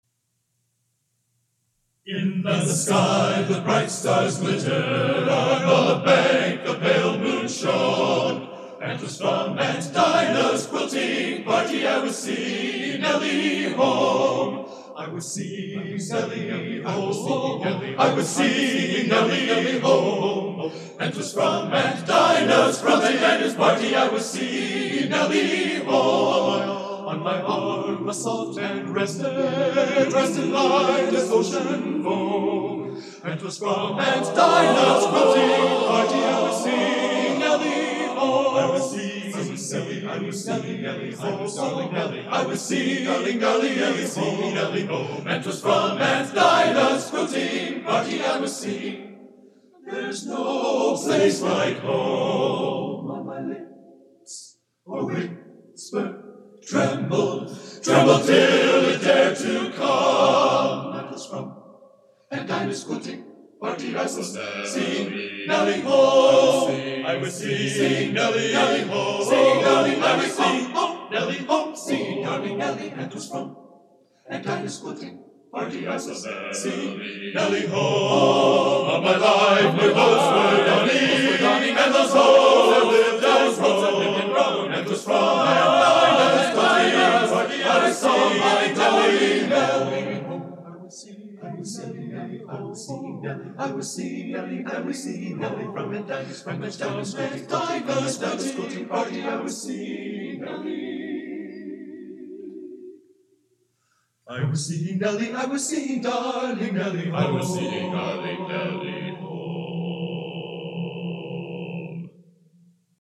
Genre: A Cappella Traditional | Type: Studio Recording